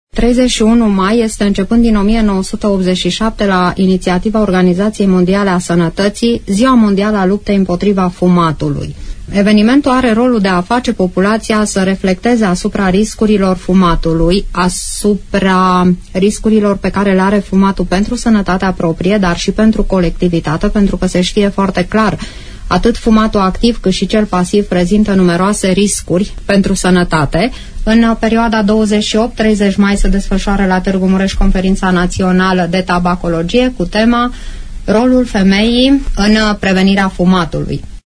invitată, azi, în emisiunea Părerea ta